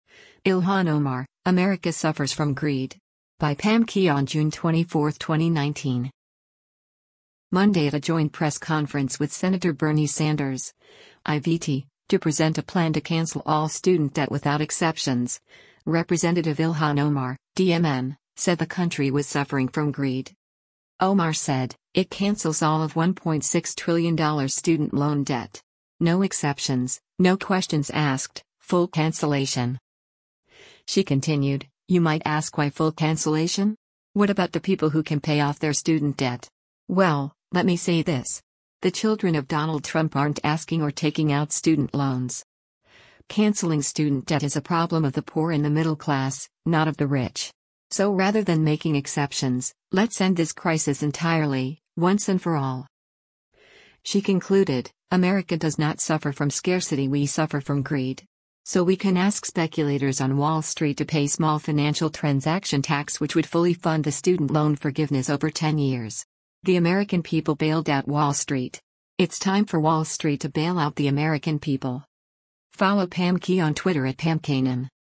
Monday at a joint press conference with Sen. Bernie Sanders (I-VT) to present a plan to cancel all student debt without exceptions, Rep. Ilhan Omar (D-MN) said the country was suffering “from greed.”